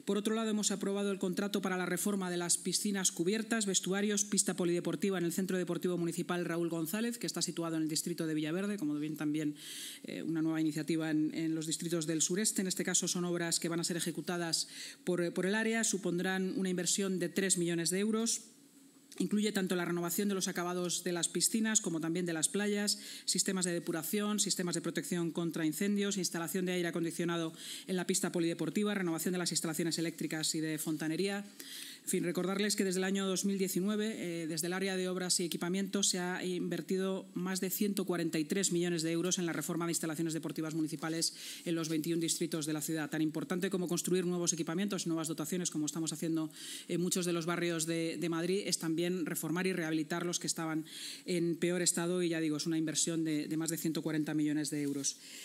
Así lo ha anunciado en rueda de prensa la vicealcaldesa y portavoz municipal, Inma Sanz, tras la reunión de la Junta de Gobierno.